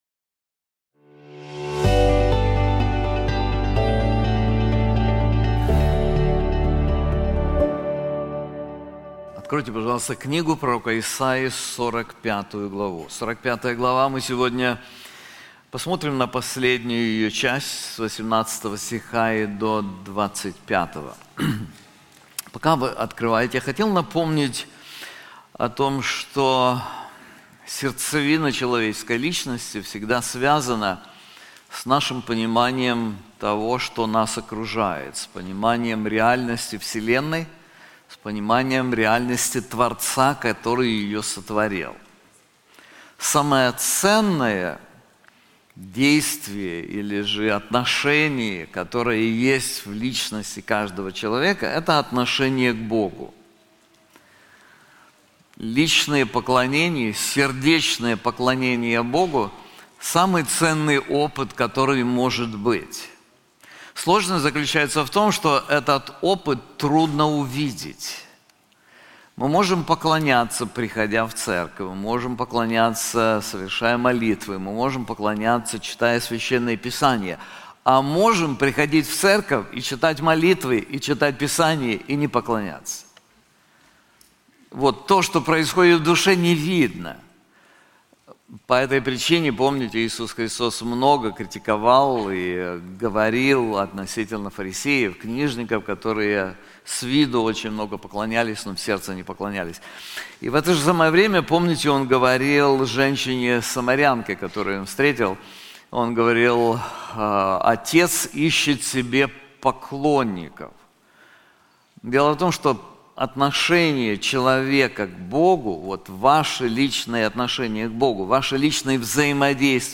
This sermon is also available in English:Only God: Why We Should Believe in Him • Isaiah 45:18-25